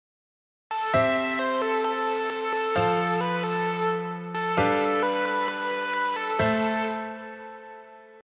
1：ノンコードトーンで始める
ノンコードトーンで始まり、同じ音が続く
同じ音が、次の小節ではコードトーンになる
サビのメロディーのサンプル1
最初のコードC部分では、ノンコードトーンの音Aが続いています。
そして2小節目のコードFになると、音AはコードFのコードトーンです。